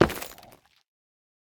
Minecraft Version Minecraft Version snapshot Latest Release | Latest Snapshot snapshot / assets / minecraft / sounds / block / nether_ore / step2.ogg Compare With Compare With Latest Release | Latest Snapshot
step2.ogg